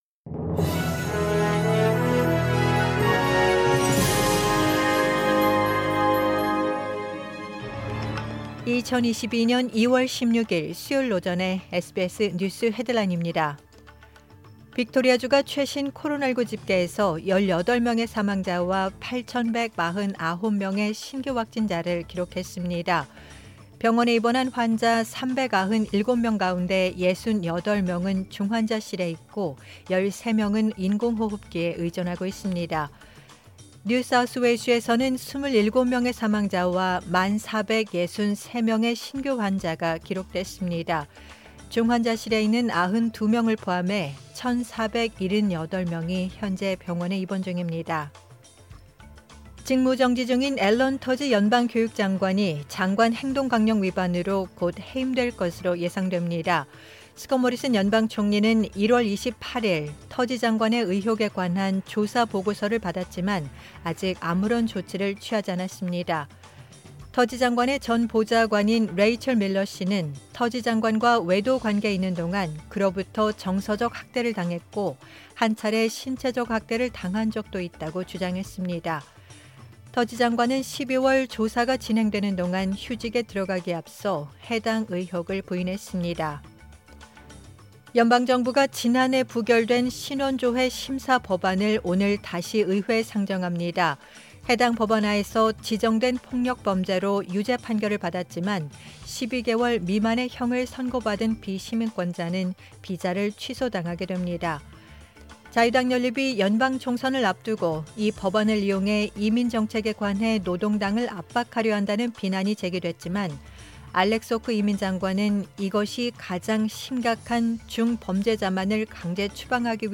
“SBS News Headlines” 2022년 2월 16일 주요 뉴스
2022년 2월 16일 수요일 오전의 SBS 뉴스 헤드라인입니다.